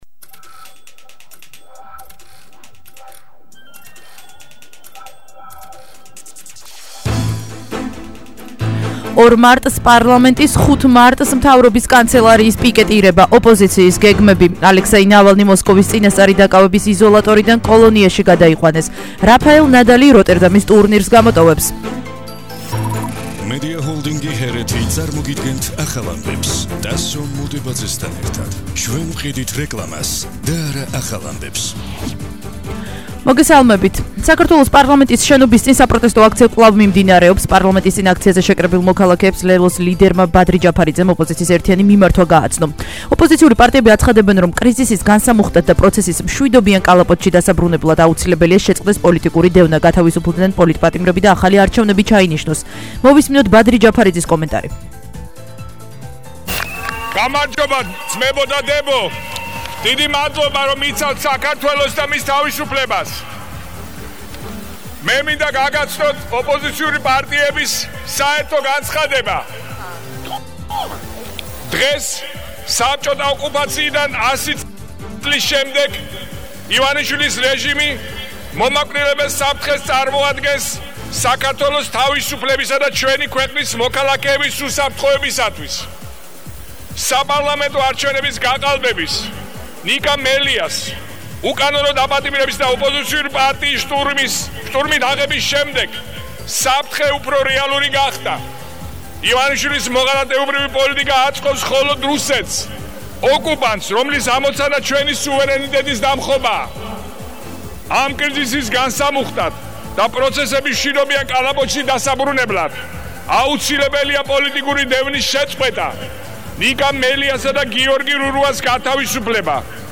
ახალი ამბები 17:00 საათზე –26/02/21 - HeretiFM